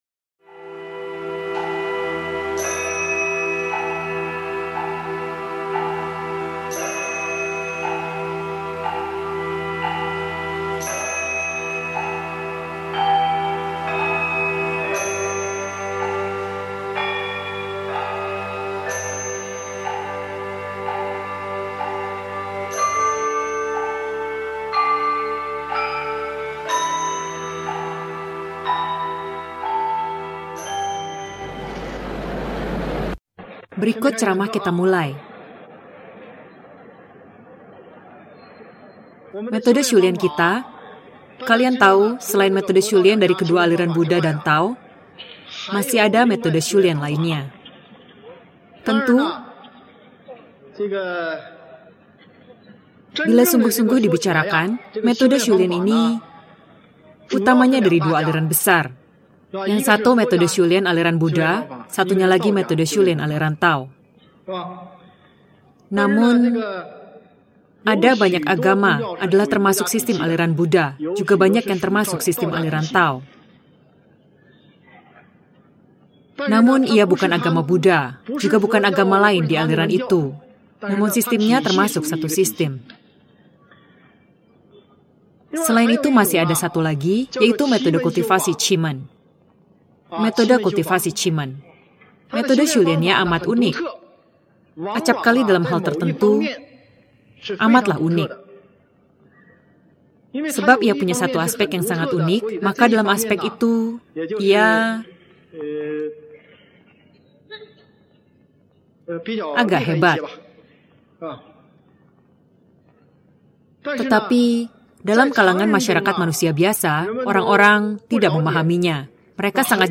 Ceramah 1